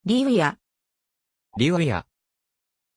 Pronunciation of Livia
pronunciation-livia-ja.mp3